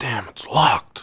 1 channel
welder-locked2.wav